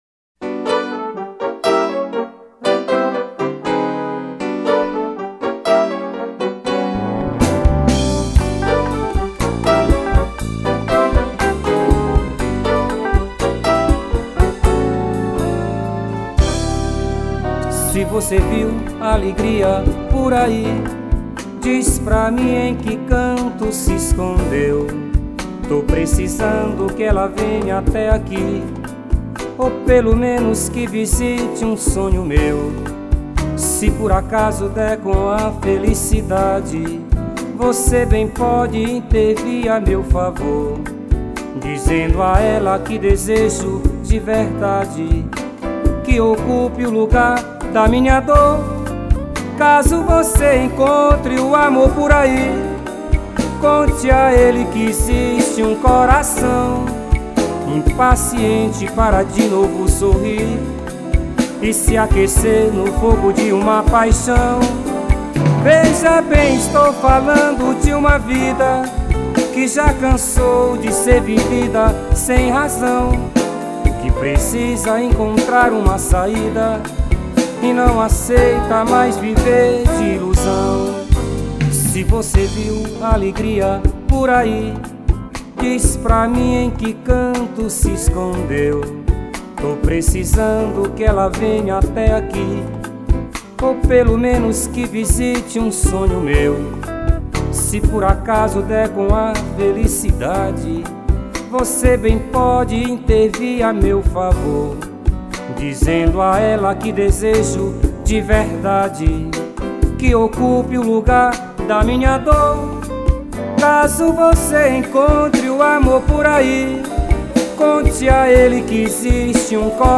2115   03:41:00   Faixa:     Bossa nova
Bateria
Voz
Baixo Elétrico 6
Guitarra
Teclados
Percussão